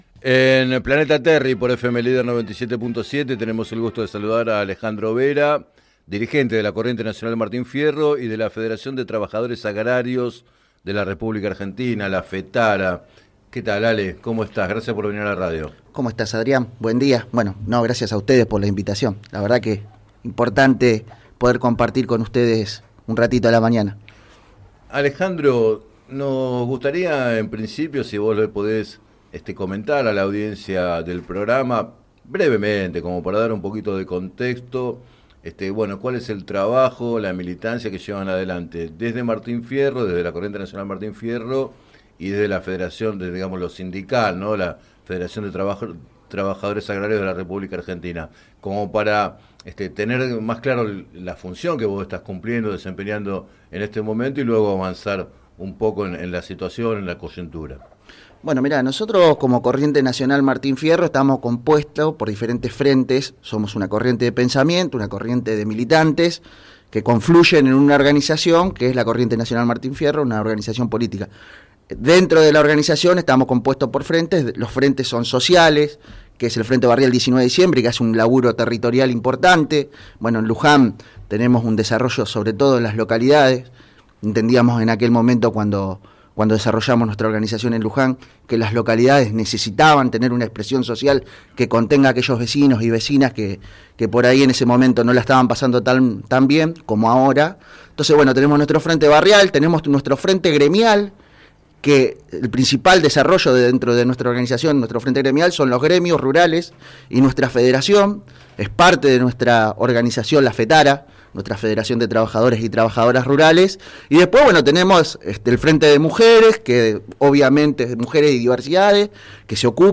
Entrevistado en Planeta Terri